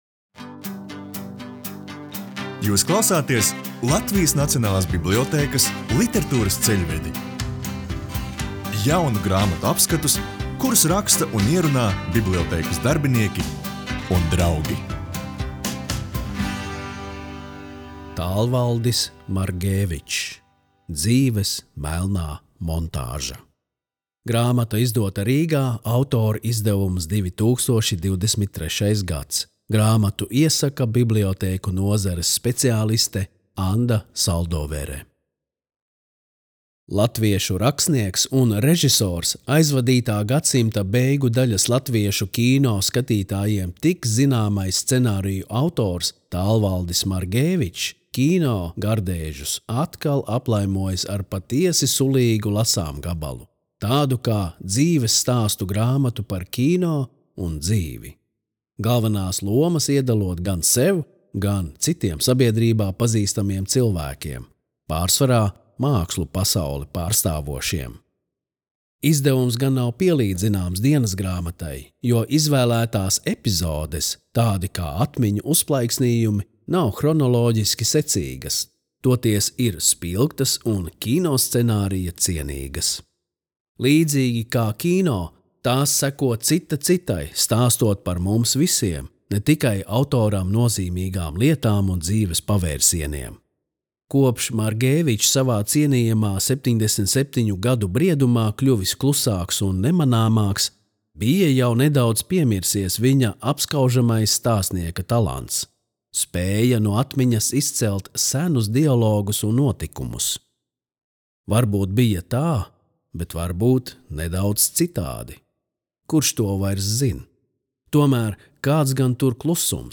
Latvijas Nacionālās bibliotēkas audio studijas ieraksti (Kolekcija)